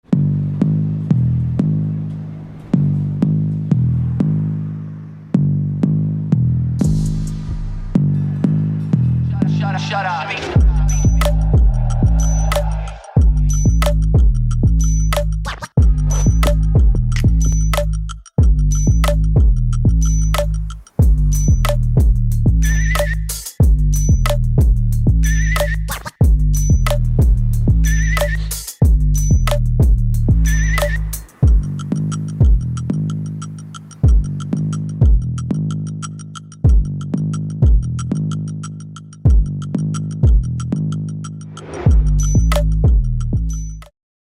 这是视频的BGM可以自取